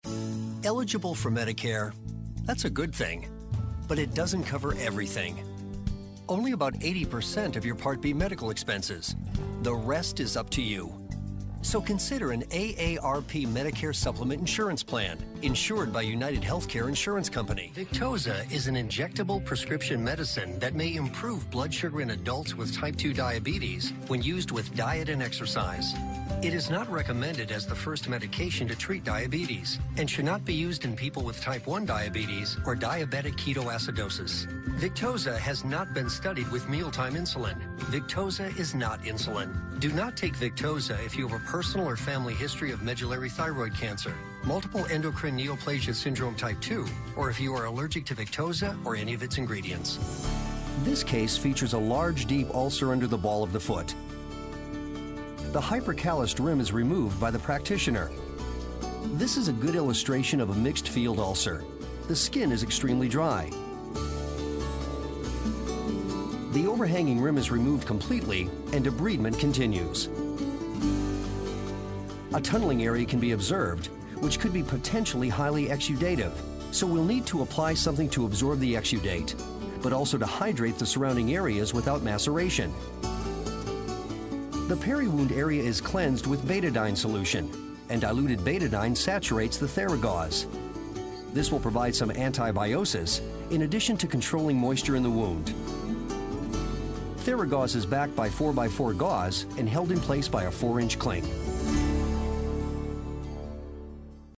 Voice talent, actor, spokesperson, comemrcials, narration, explainer videos, telephony.
Sprechprobe: Sonstiges (Muttersprache):